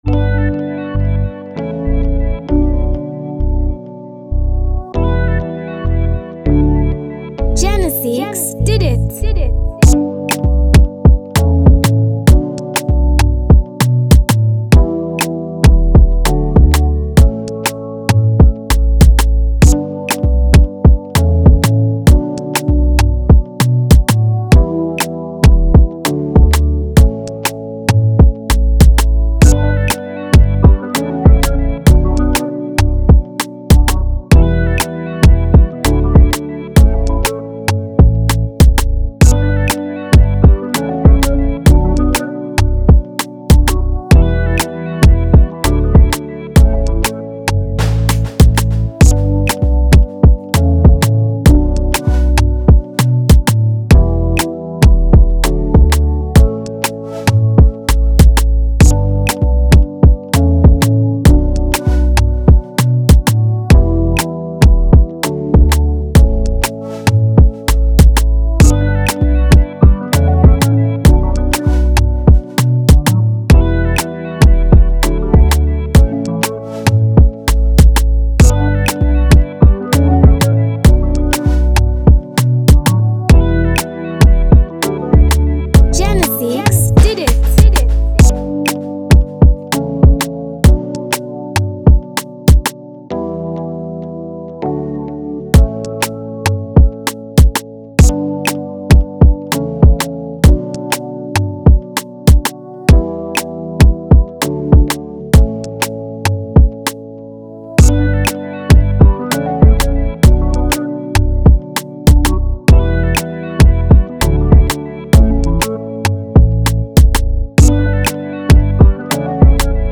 boasts a cool beat with a Nigerian flair